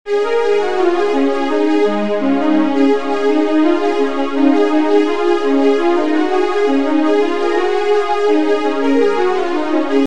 The assignment for the constant n for example could be replaced by a function, which repeats after the period of the canon.